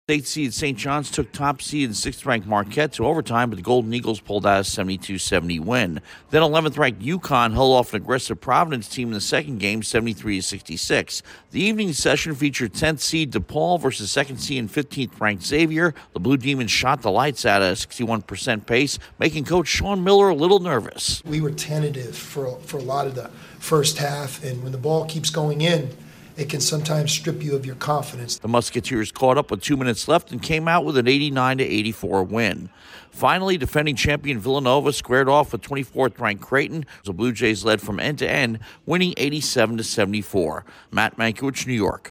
The Big East Tournament is down to four teams. Correspondent